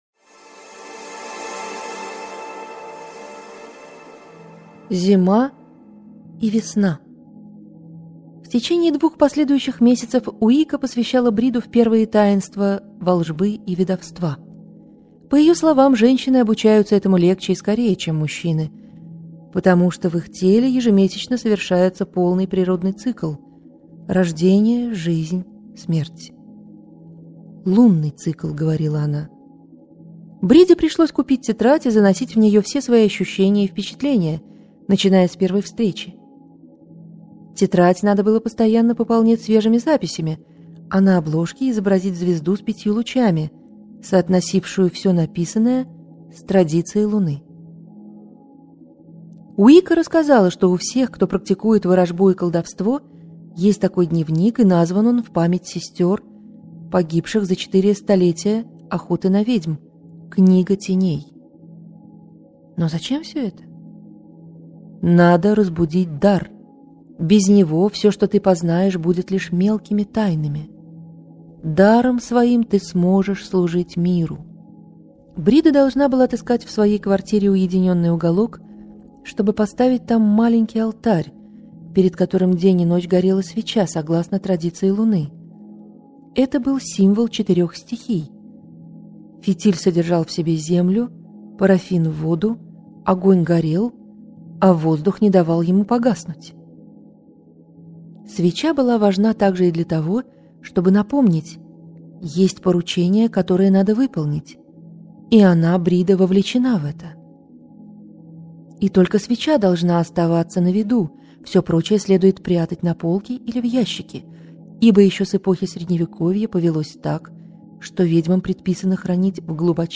Аудиокнига Брида - купить, скачать и слушать онлайн | КнигоПоиск